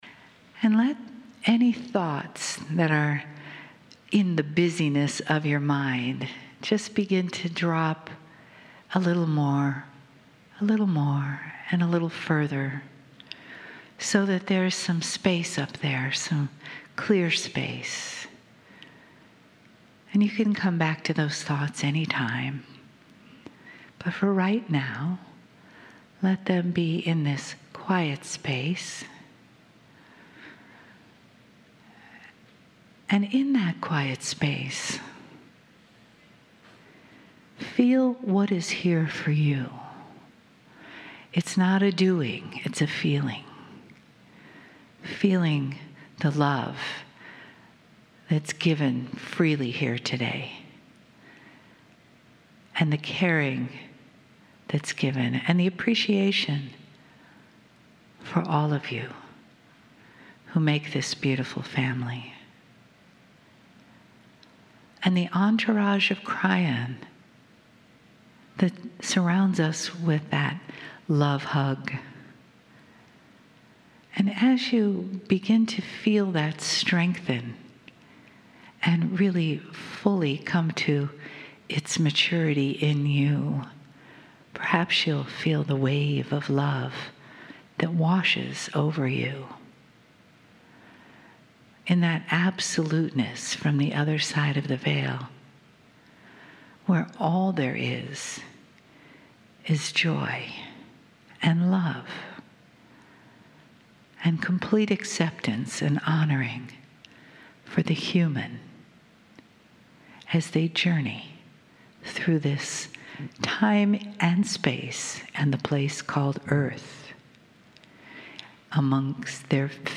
Grand Rapids, Michigan Saturday & Sunday - Aug 25, 26, 2018
KRYON CHANNELLING